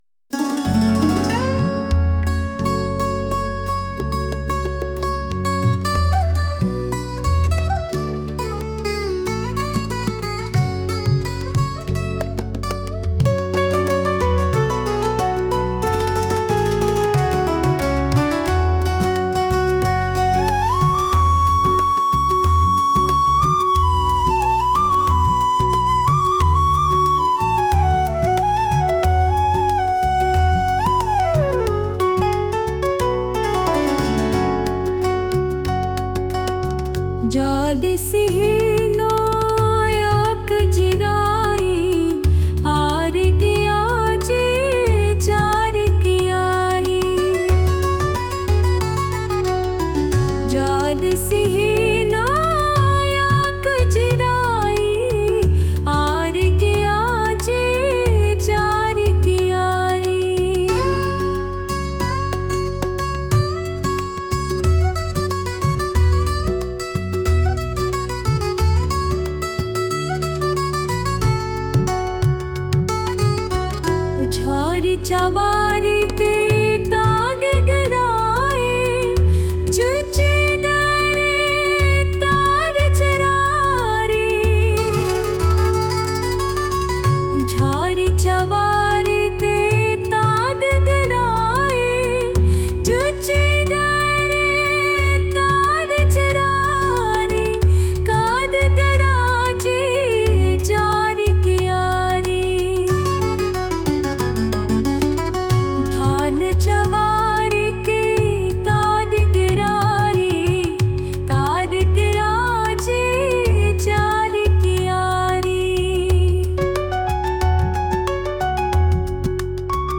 world | soulful | classical